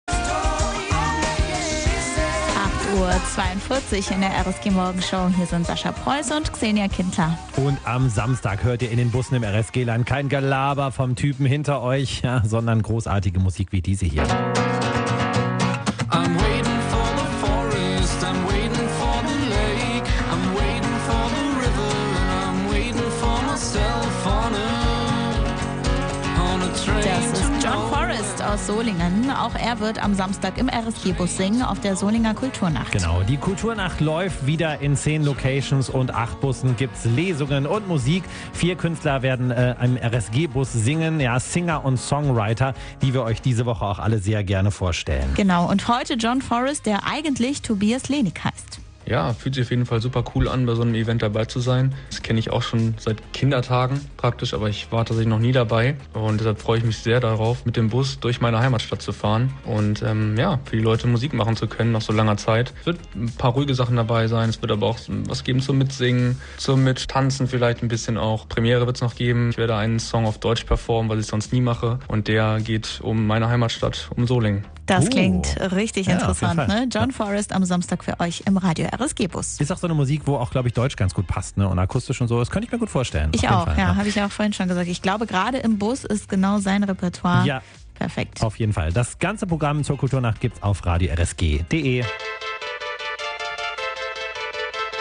ruhigem, harmonischem Fingerpicking
Live spielt er neben seinen eigenen Liedern auch einige Coversongs seiner Idole.
Jugendliche und Erwachsene aller Altersklassen in Gitarre und Schlagzeug. https
Kultur Nacht Solingen 2022